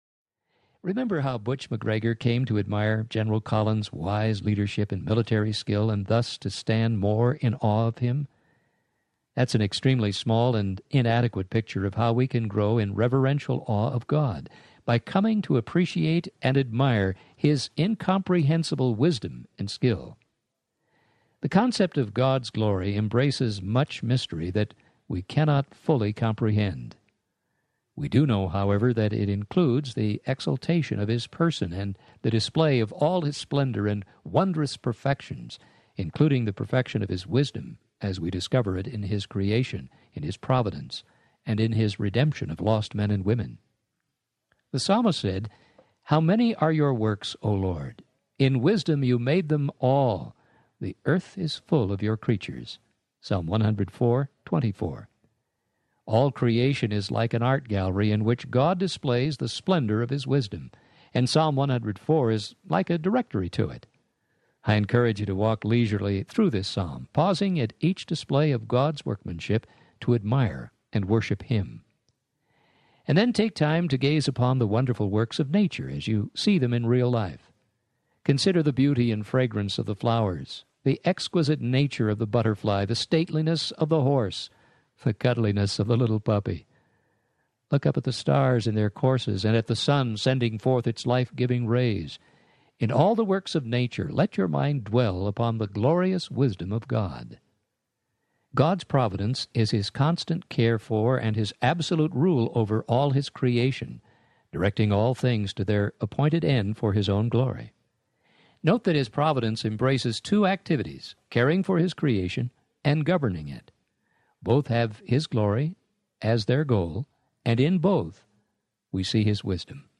The Joy of Fearing God Audiobook
3 Hrs. – Abridged